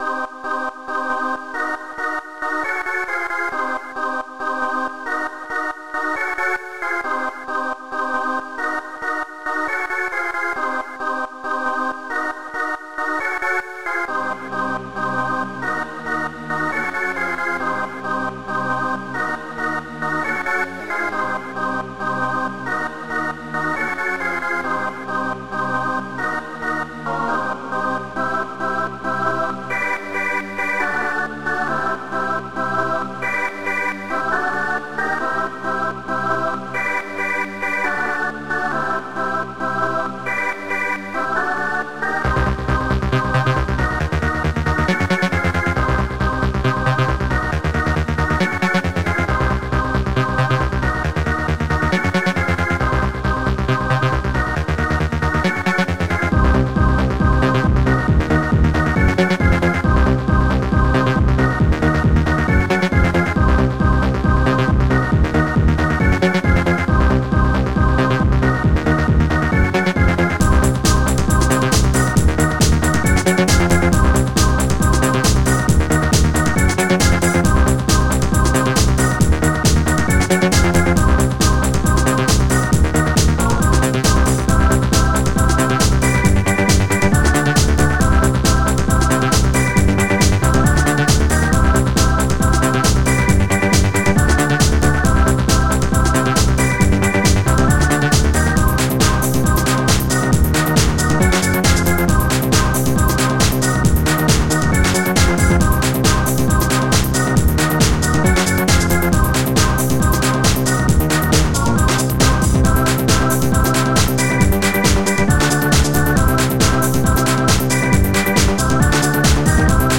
Oktalyzer Module  |  1994-04-30  |  342KB  |  2 channels  |  44,100 sample rate  |  4 minutes, 27 seconds
clap
Hardtekknodrum3
HiHat2
Shaker